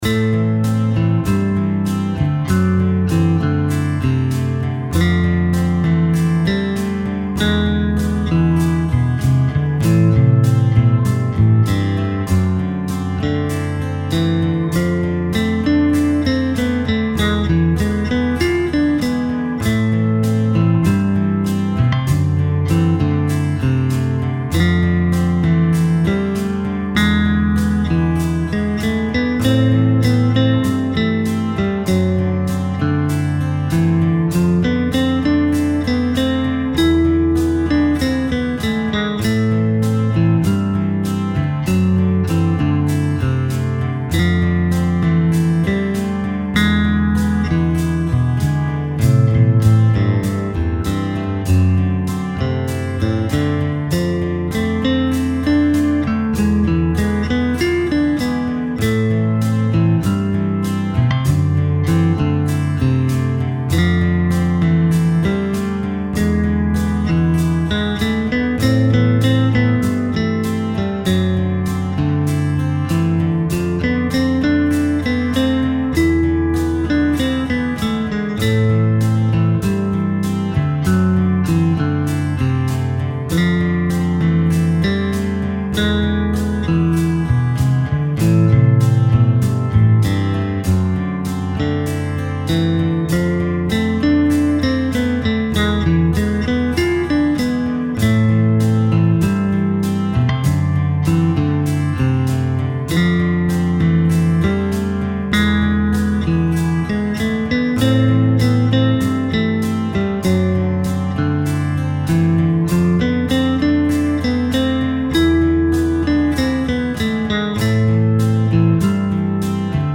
音楽ジャンル： ロック
楽曲の曲調： SOFT